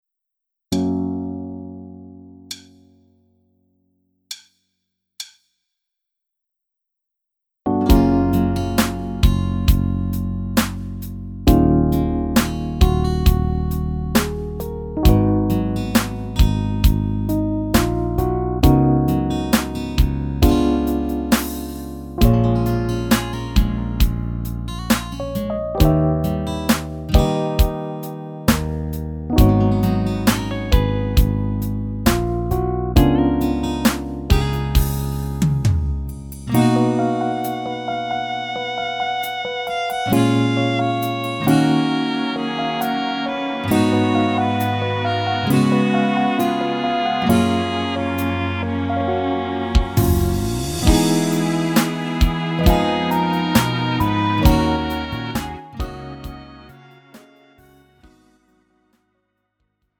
음정 원키 4:03
장르 가요 구분 Lite MR